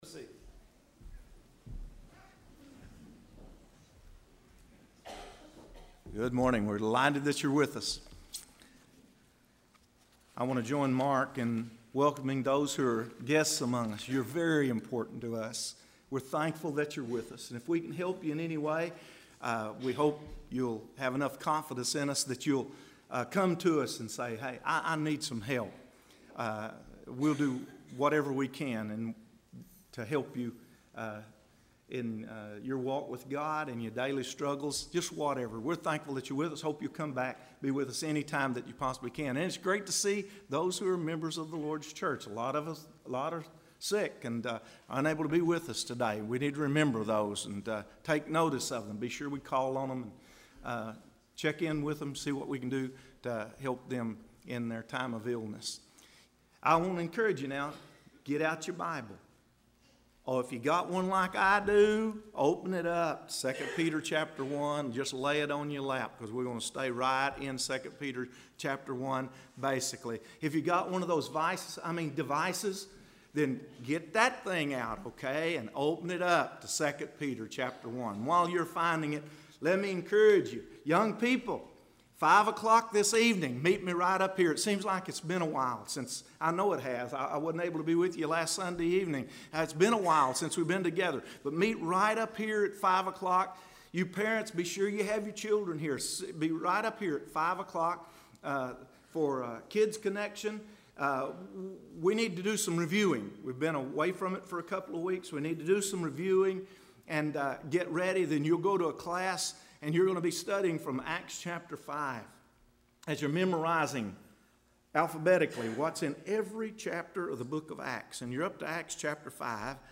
Sunday AM – Christian Growth:It’s Essentiality,Purpose & Elements